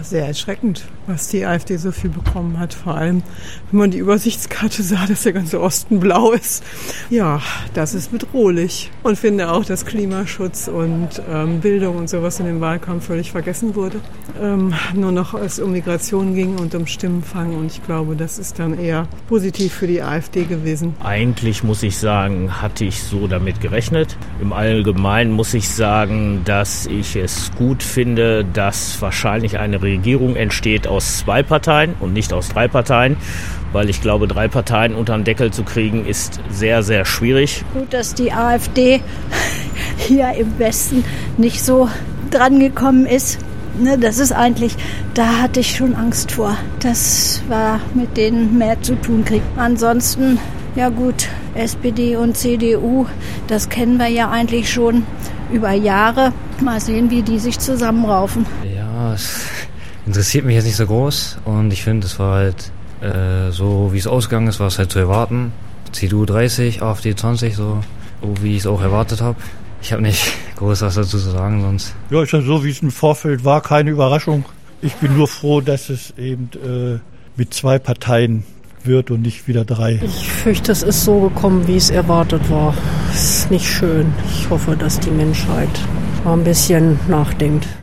Weserbergland: Umfrage zur Bundestagswahl